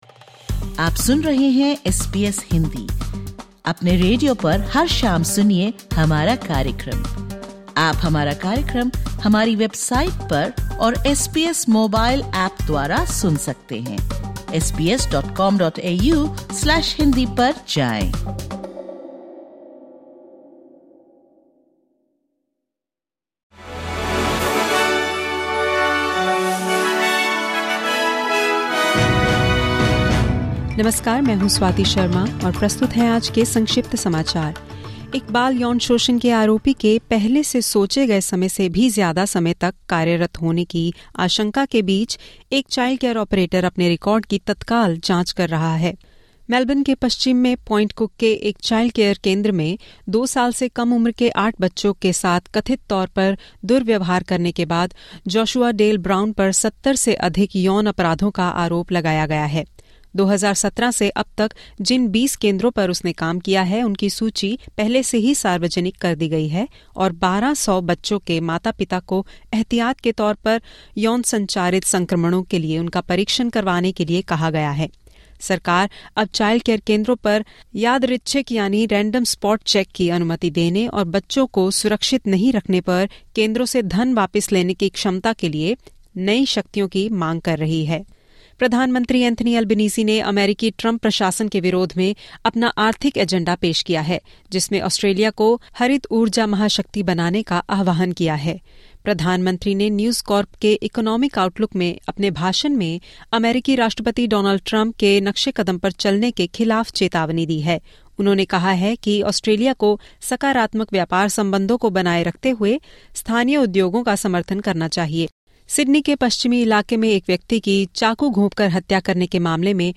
Top news: Government seeks powers to allow for random spot checks after alleged childcare abuse